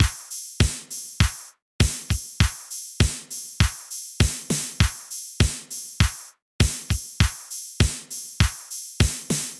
室外大风大雨
描述：在室内录窗外的风声雨声
声道立体声